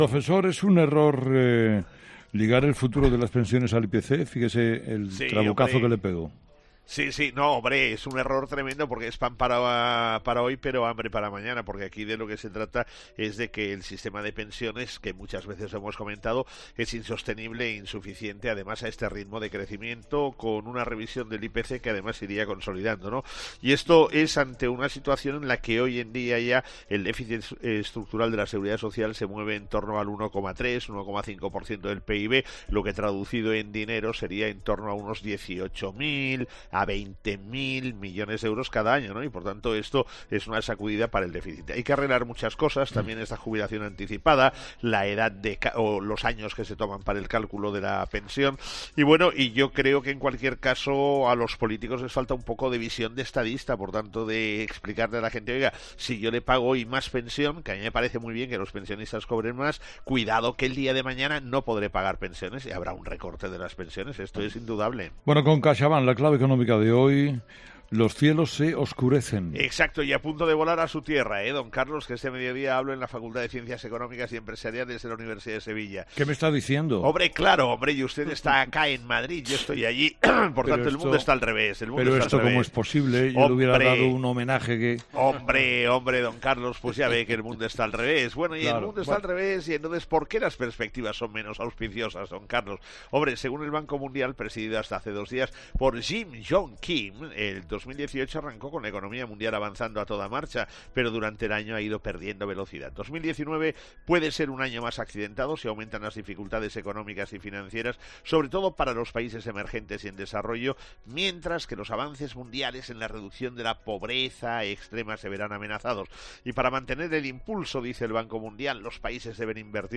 Todas las mañanas la actualidad económica en 'Herrera en COPE' con el profesor Gay de Liébana.